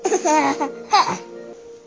闷声笑
描述：一声低沉的傻笑。
标签： 闷声笑 马弗 咯咯地笑 傻笑
声道立体声